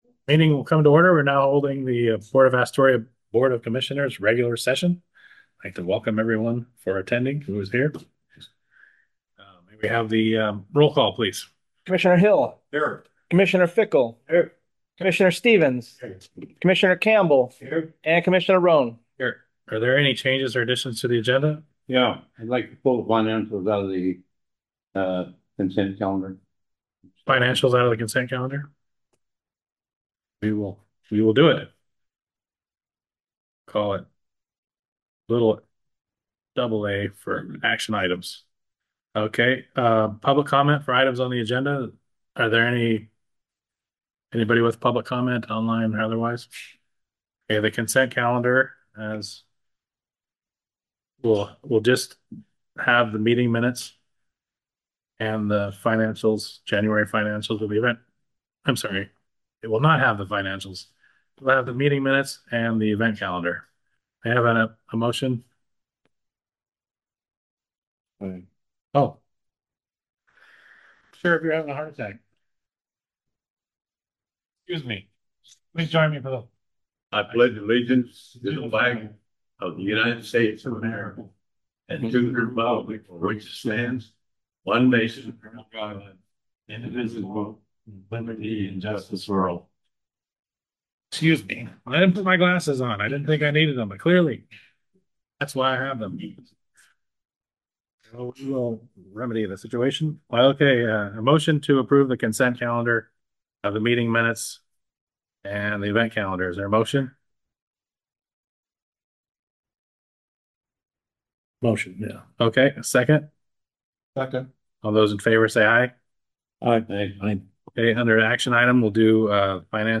Commission Regular Meeting
422 Gateway Avenue Suite 100, Astoria, OR, at 4 PM